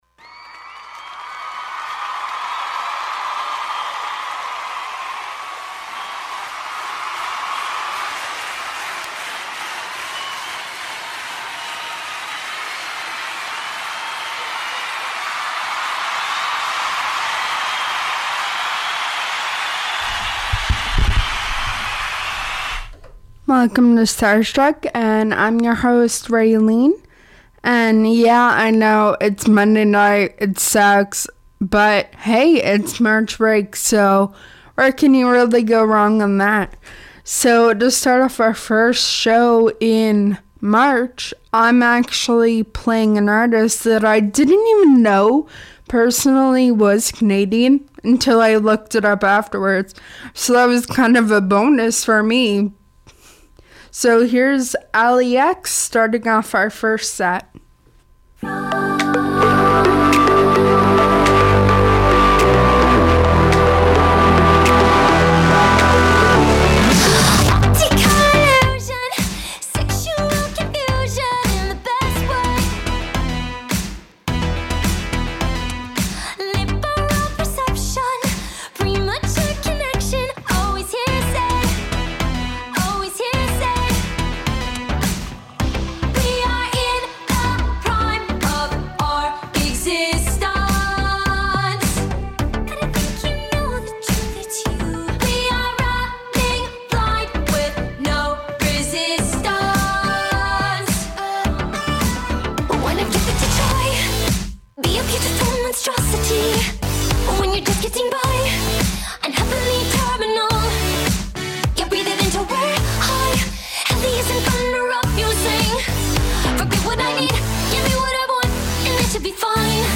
An Open Format Music Show - Pop, Acoustic,Alternative Rock,as well as Local/Canadian artists